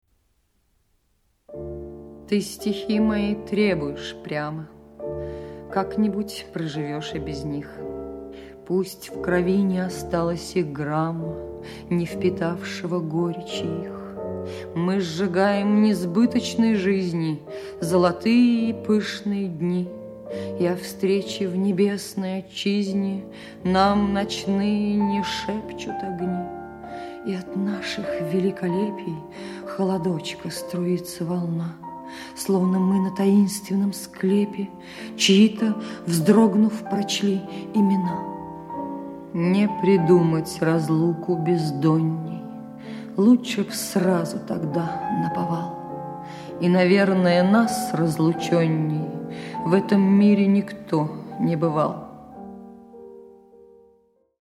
1. «Маргарита Терехова (Largo du concerto pour orgue e – Через много лет. Последнее слово (Анна Ахматова)» /